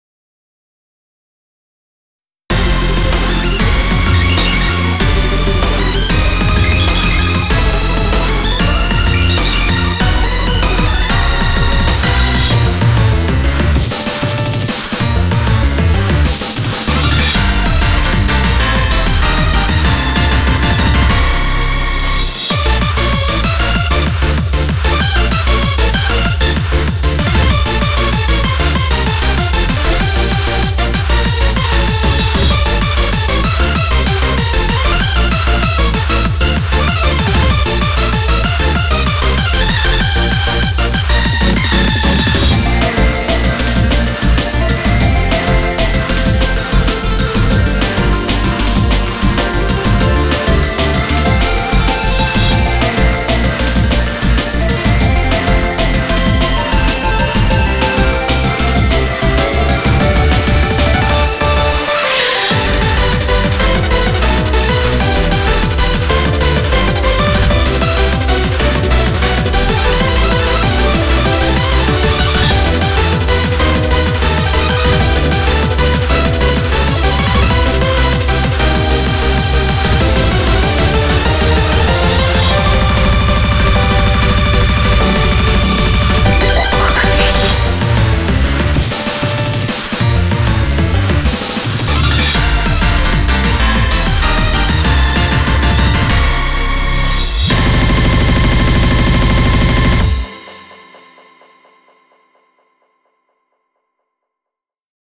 BPM96-192
Audio QualityPerfect (High Quality)